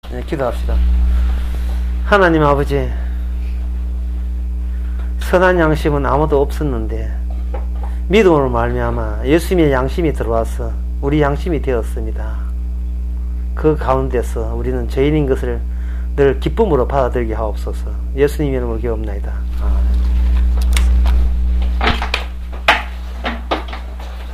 기도
기도.mp3